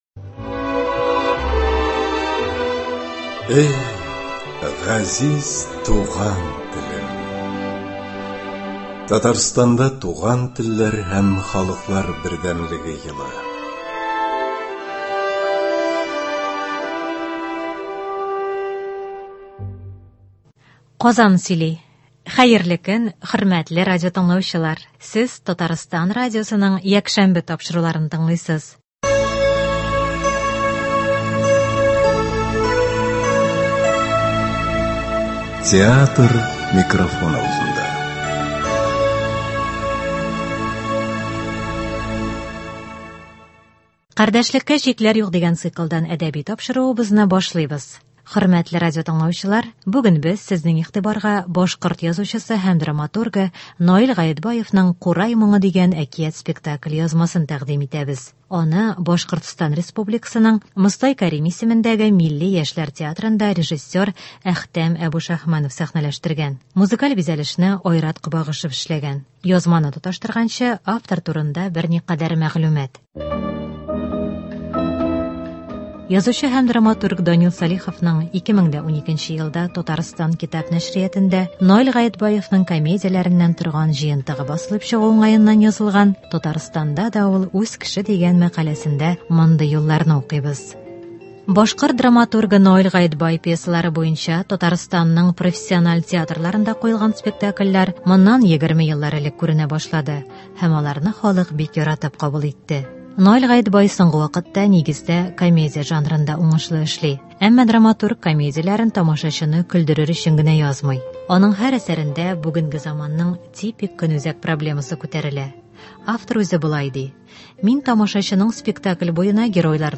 Мостай Кәрим ис.Башкорт милли яшьләр театры спектакленең радиоварианты.